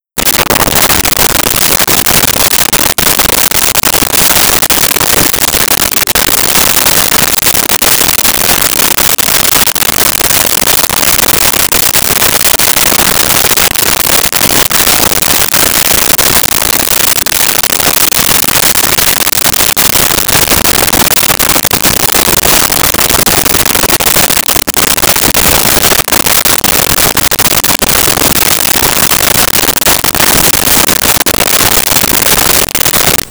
10 Speed Slow Pedal
10 Speed Slow Pedal.wav